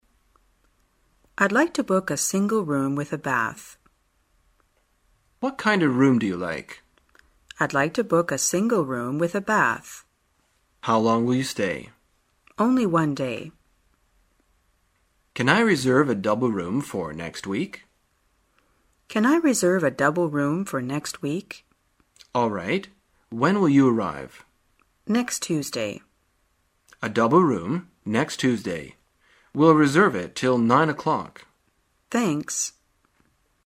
在线英语听力室生活口语天天说 第142期:怎样预订房间的听力文件下载,《生活口语天天说》栏目将日常生活中最常用到的口语句型进行收集和重点讲解。真人发音配字幕帮助英语爱好者们练习听力并进行口语跟读。